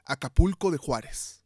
Acapulco de Juárez (Spanish: [akaˈpulko ðe ˈxwaɾes] (audio speaker icon
Acapulco_de_Juárez_-_es_-_mx.ogg